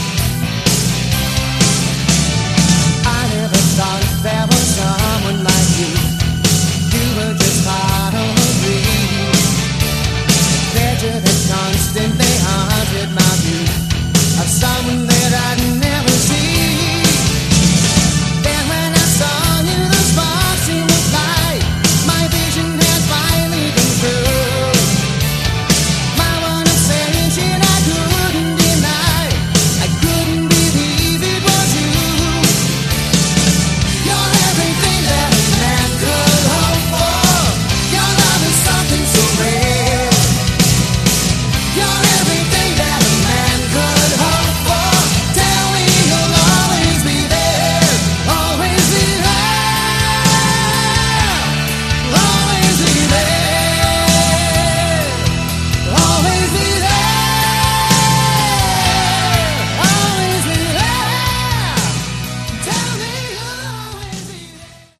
Category: AOR
vocals, bass
drums, backing vocals
guitars, backing vocals
keyboards, backing vocals